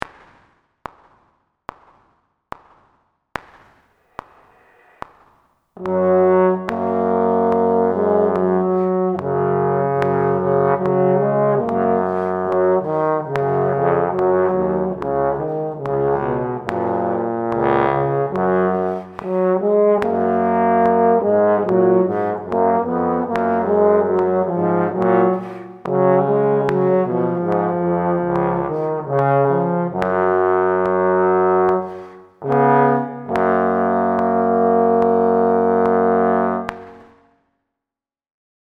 Here’s the audio of the (individual) harmony parts.
Maj-06-G-flat.mp3